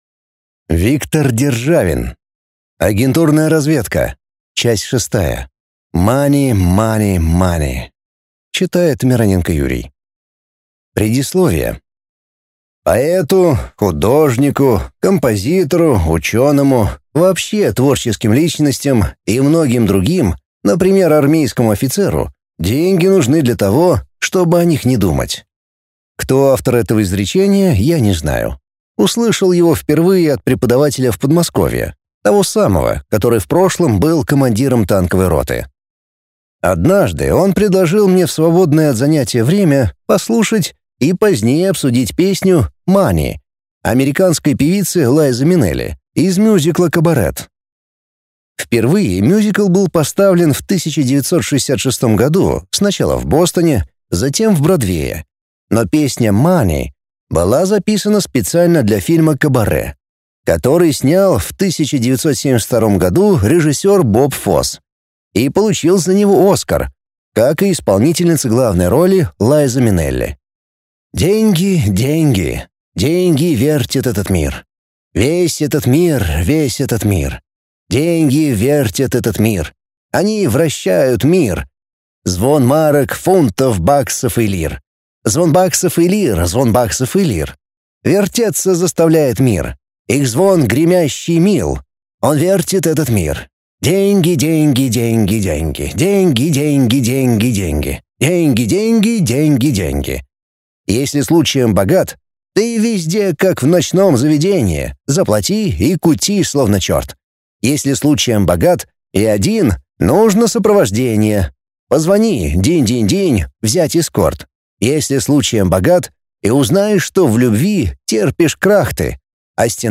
Аудиокнига Агентурная разведка. Часть 6. Money, money, money | Библиотека аудиокниг